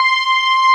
LARRYPAD2.wav